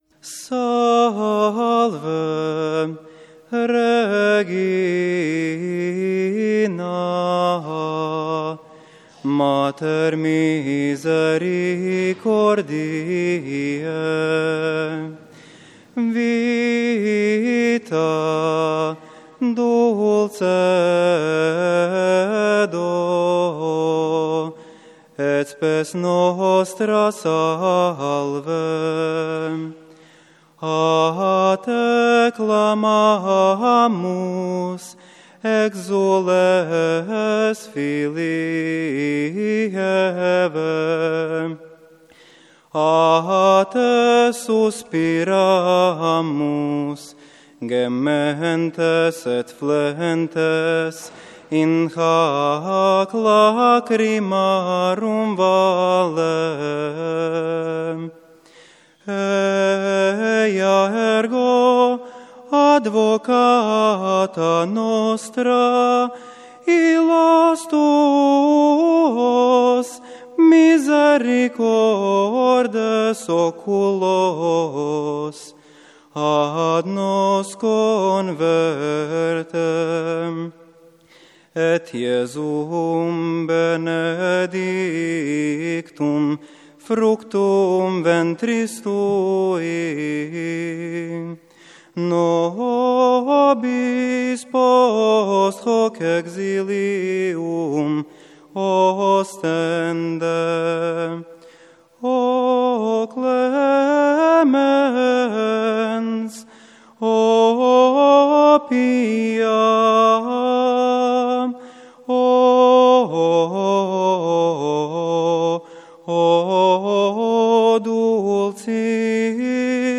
SALVE REGINA - svečani koralni napjev
Liturgijske
Koral